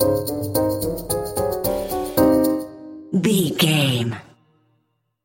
Aeolian/Minor
percussion
flute
orchestra
piano
silly
circus
goofy
comical
cheerful
perky
Light hearted
secretive
quirky